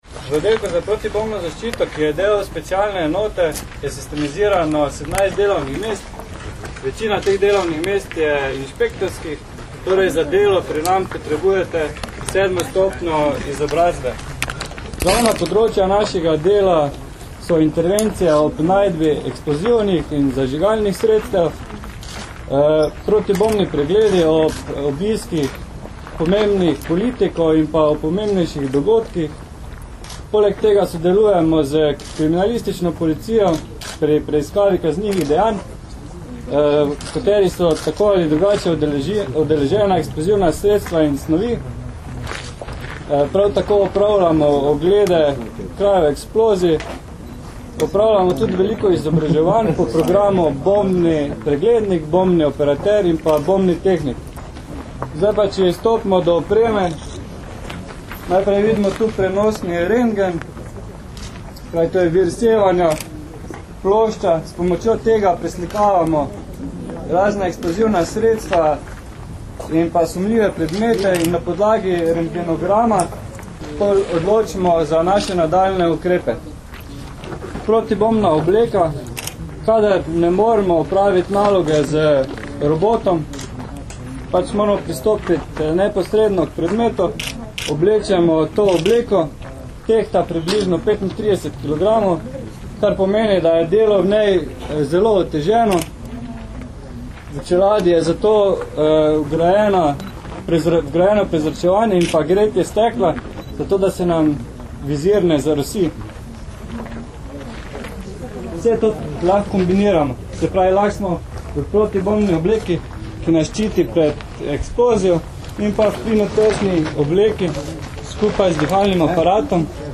Zvočni posnetek pripadnika Oddelka za protibombno zaščito o njegovih nalogah in opremi (mp3)